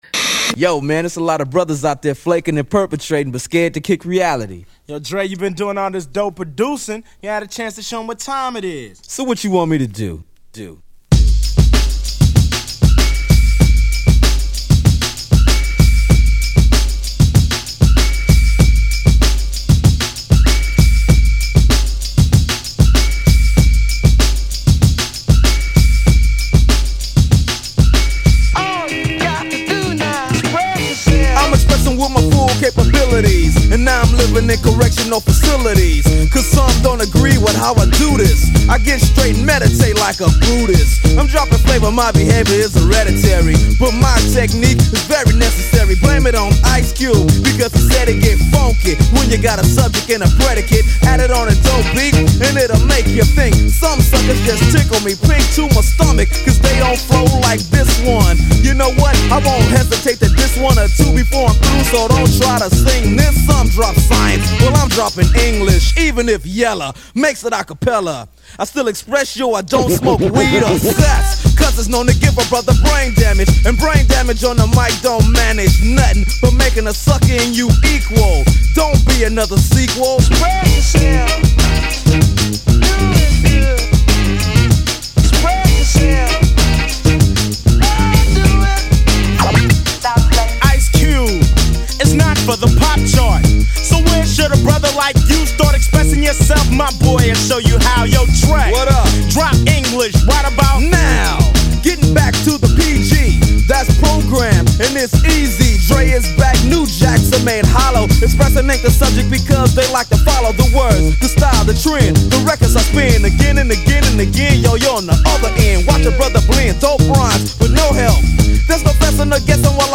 Tags: Metal Drum n bass hip hop